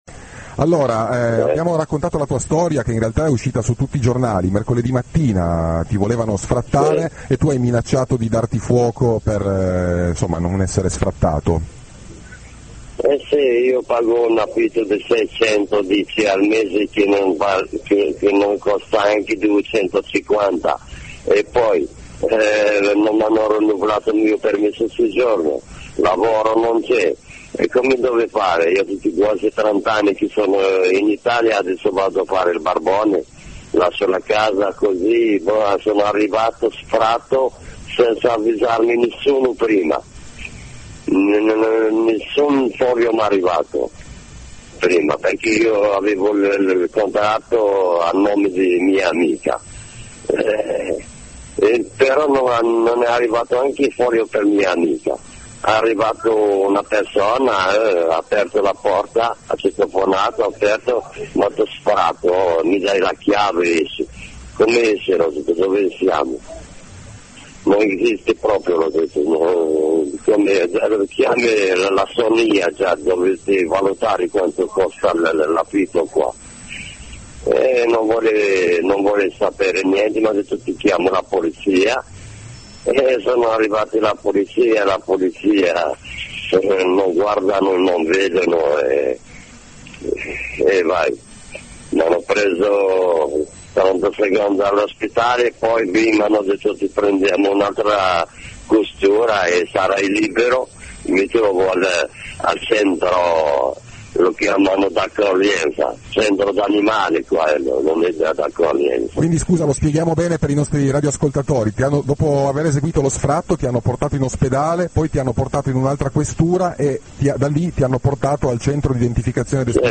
Ascolta tutta la storia, dalla viva voce dello sfrattato-recluso al telefono con Radio Blackout 105.250, oppure